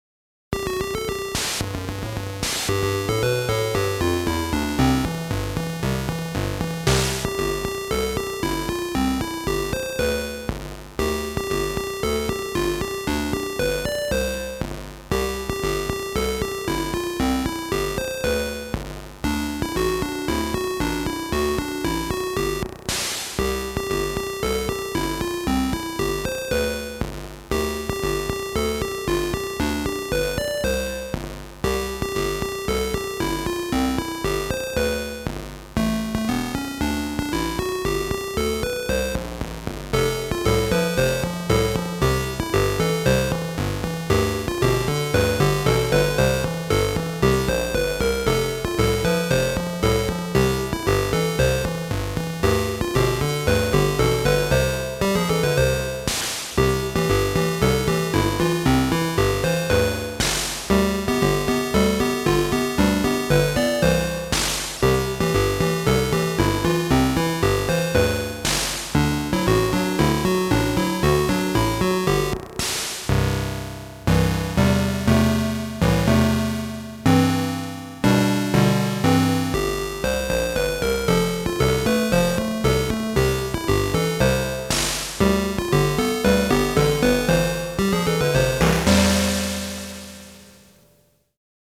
"- POLKA"
Atari-ST Tunes